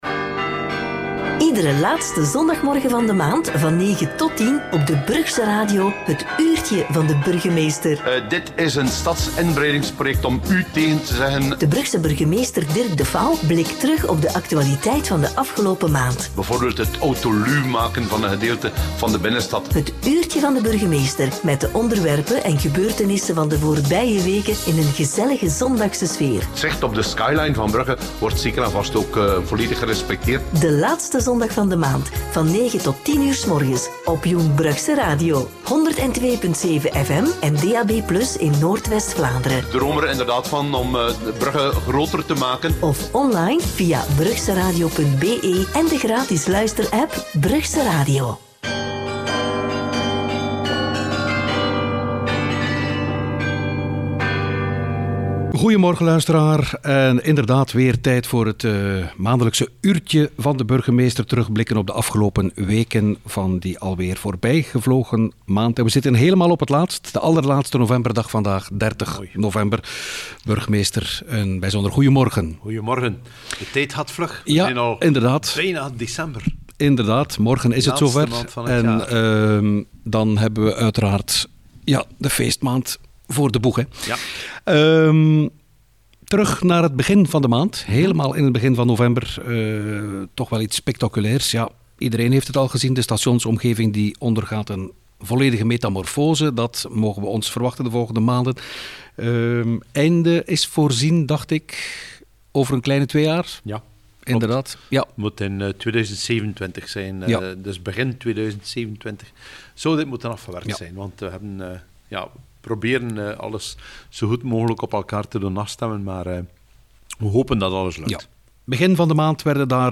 in gesprek met Dirk De fauw